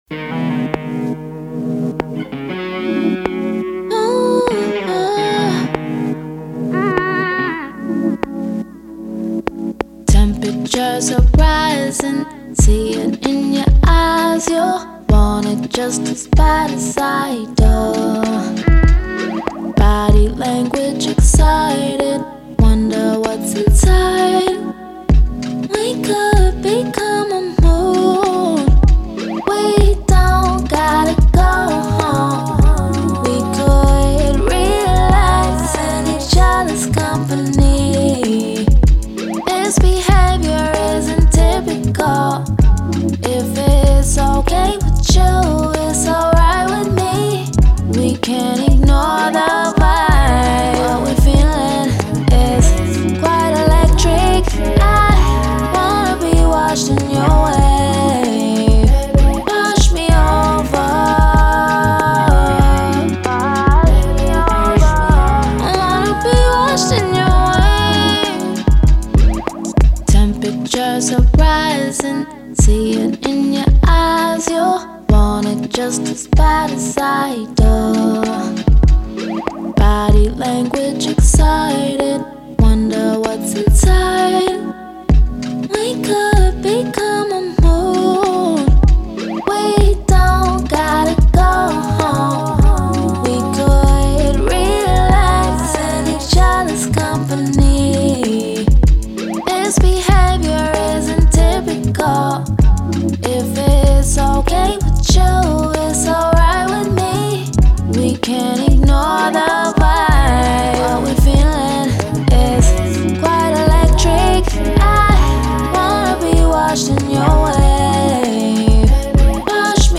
R&B
C# Minor